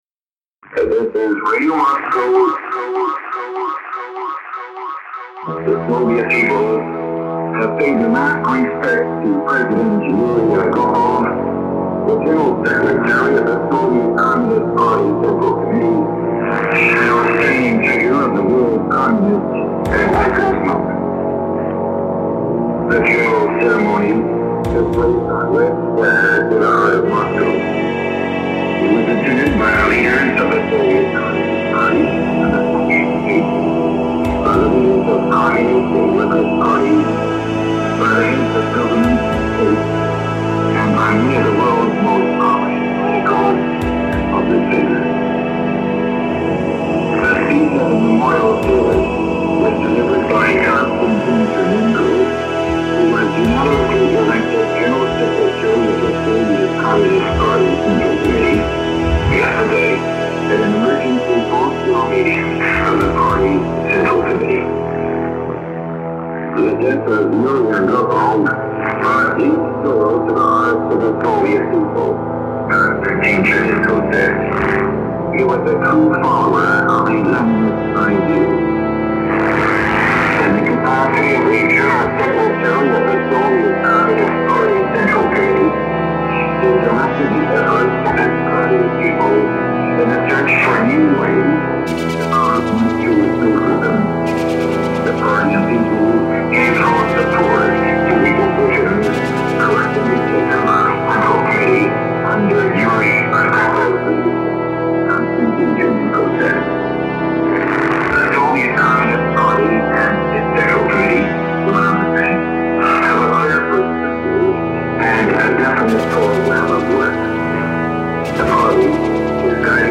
I wanted to do something a bit like Benjamin Britten's 'Russian Funeral' so it's mainly a horn based instrumental. There are three main parts to it - intro, march and bridge."